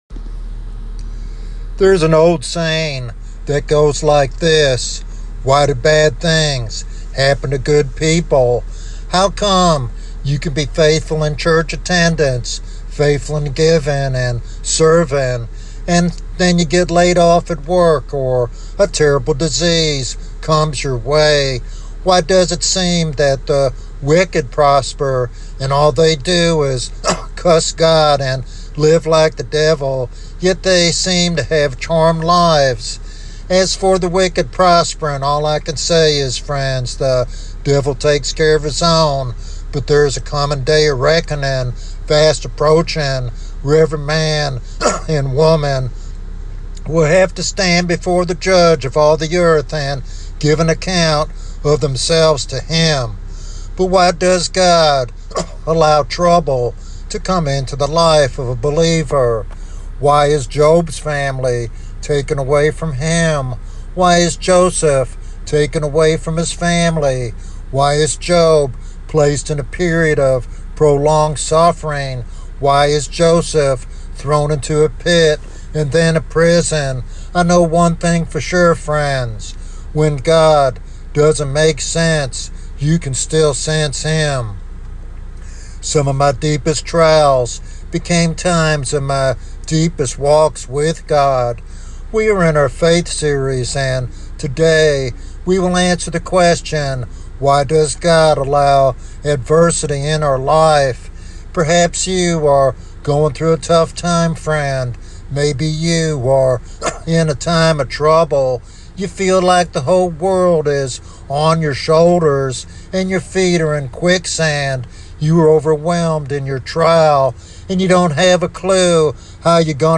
The sermon encourages believers to trust God's sovereign plan and cooperate with Him during difficult times to grow in faith and spiritual maturity.